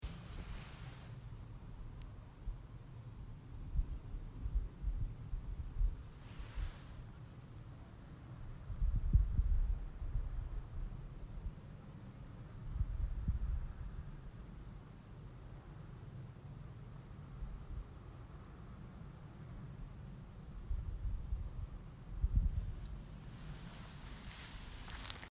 geh weinbergstrawanzen ferner flieger wien
geh_weinbergstrawanzen_ferner_flieger_wien.mp3